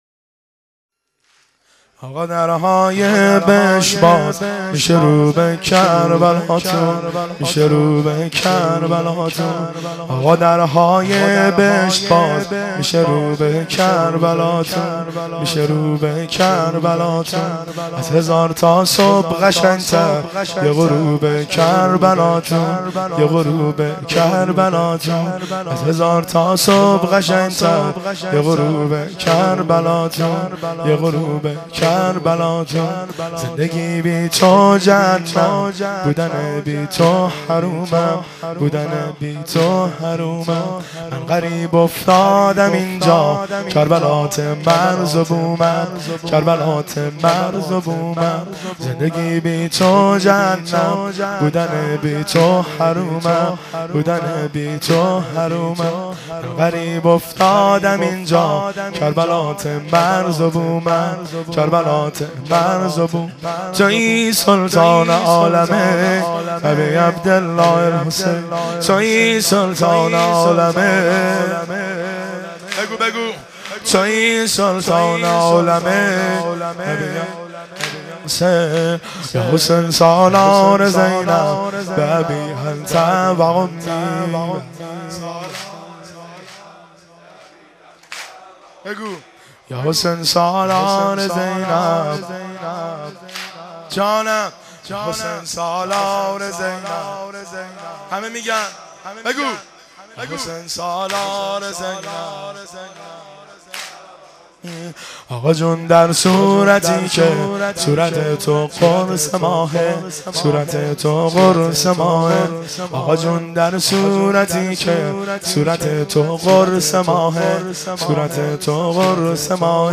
واحد شب 6 محرم الحرام 1390 هیئت محبان الحسین